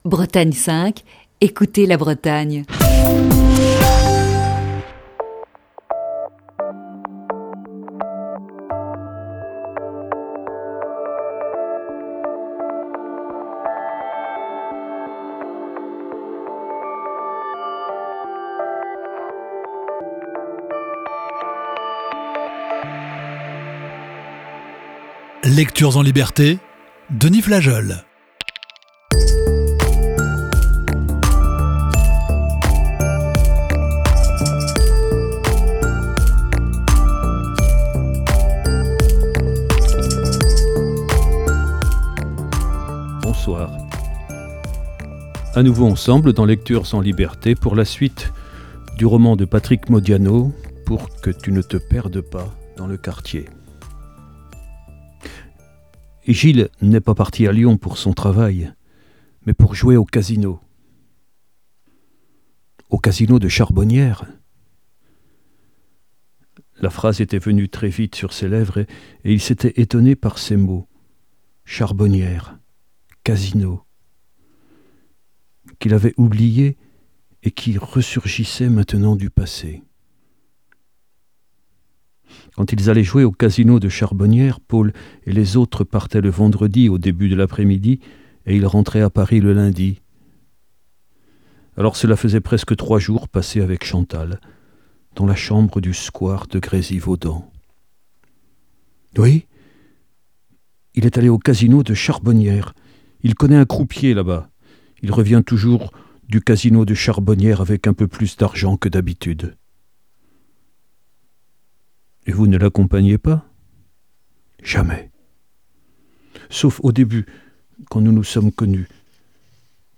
lit la quatrième partie de ce récit.